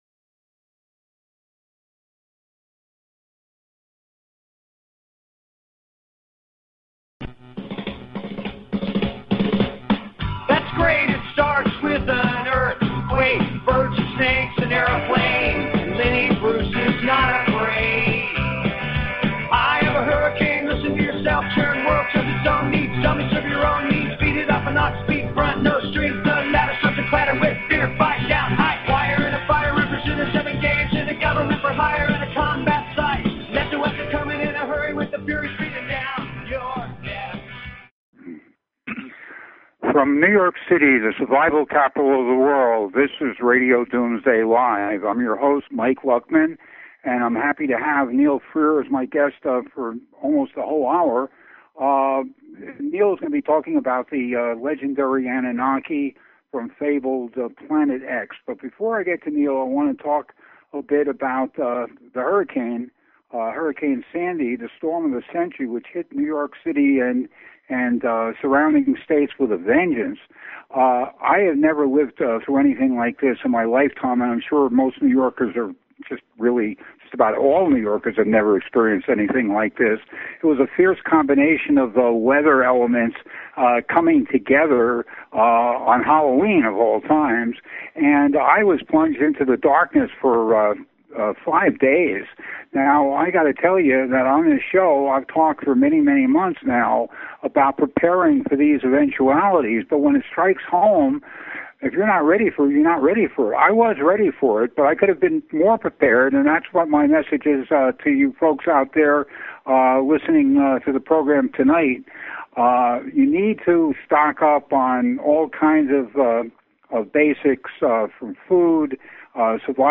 Talk Show Episode, Audio Podcast, Starship_One_Radio and Courtesy of BBS Radio on , show guests , about , categorized as